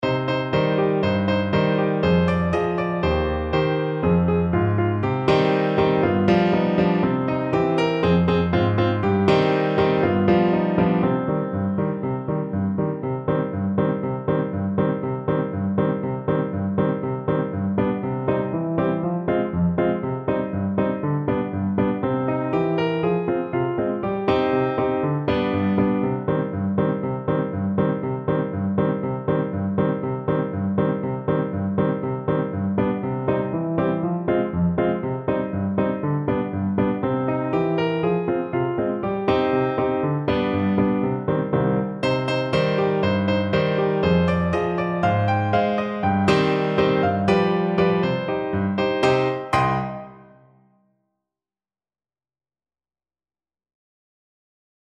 2/4 (View more 2/4 Music)
Allegro (View more music marked Allegro)